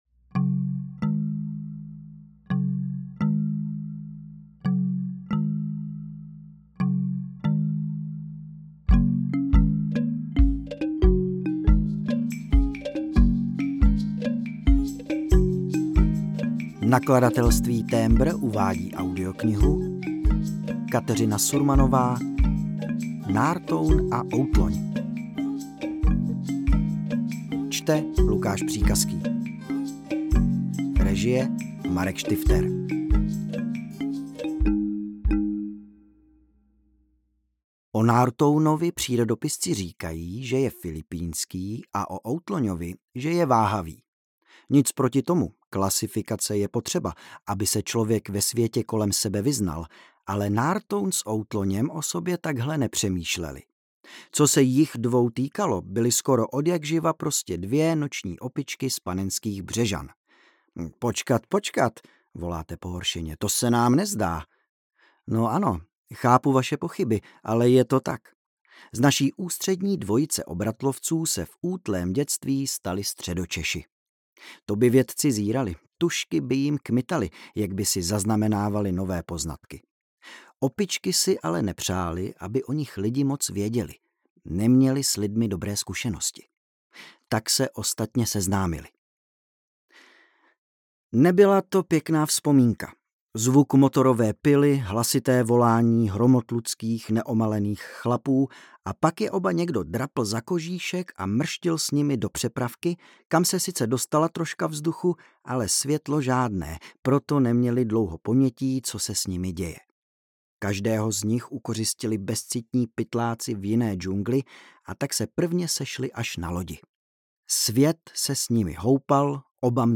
Nártoun a Outloň audiokniha
Ukázka z knihy
• InterpretLukáš Příkazký